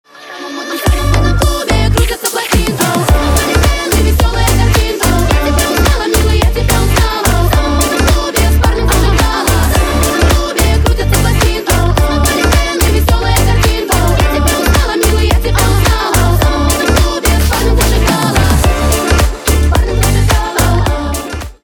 поп
ремиксы , битовые , басы , качающие , клубные